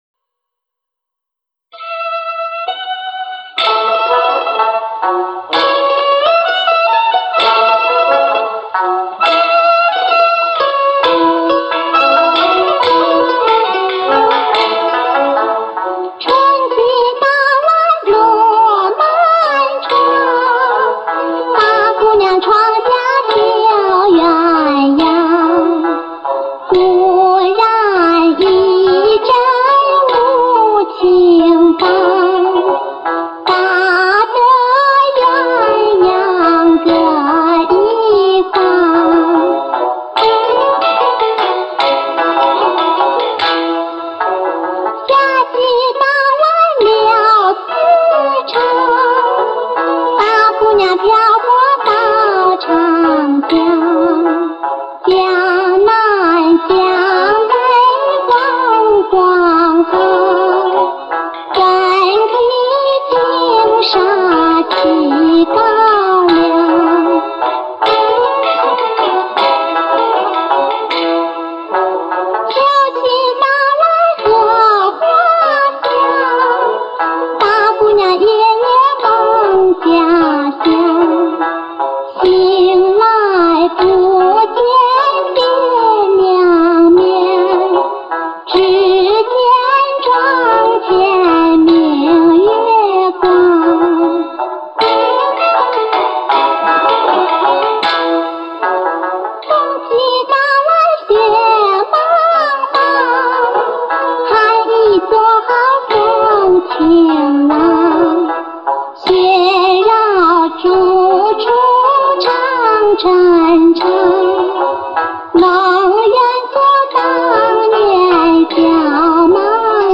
黑胶转制 精品制作
上 世 纪 三 十 年 代 绝 世 录 音